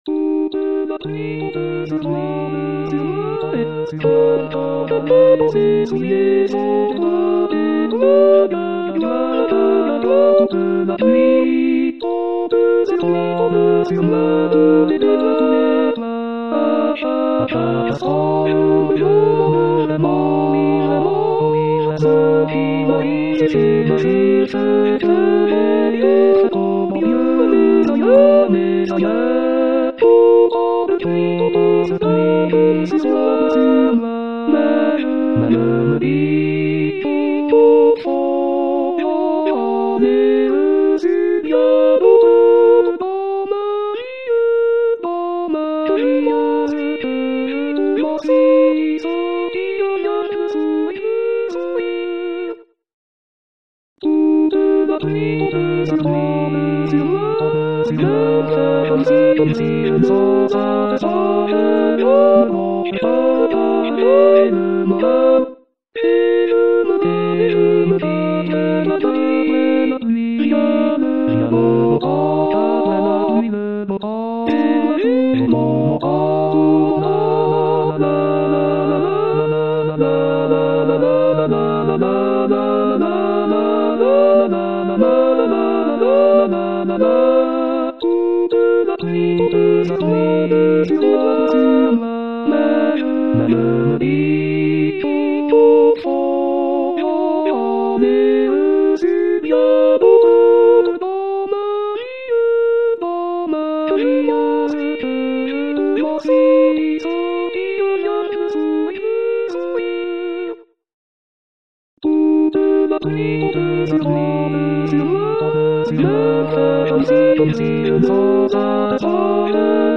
Liste des partitions Moulin rouge Soprano Alto Hommes Choeur Toute la pluie tombe sur moi Paroles Française de Maurice Teze Paroles et Musique de Hal David , Burt Bacharad Harmonisation de Jacques Berthe